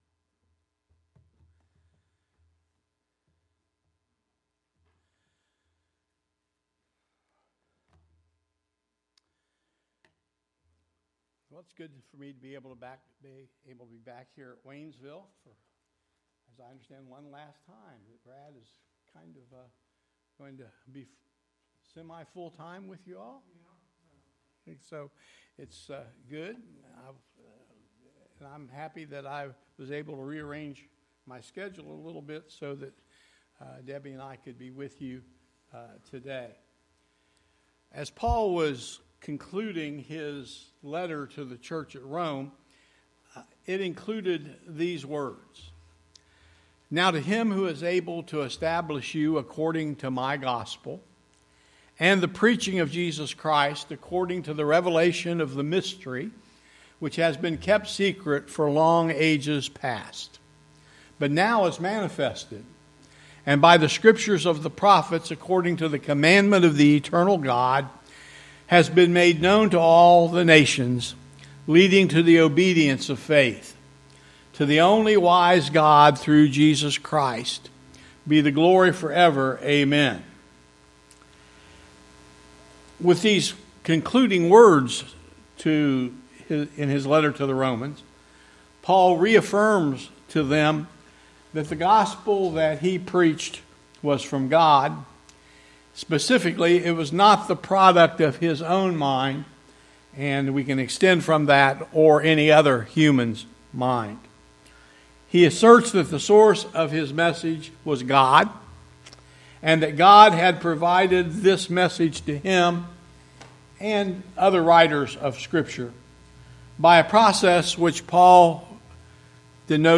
The sermon teaches that God has revealed Himself through creation, Scripture, and ultimately His Son. Both general and special revelation create accountability. Scripture is inspired, authoritative, and sufficient, and Christ perfectly reveals the Father.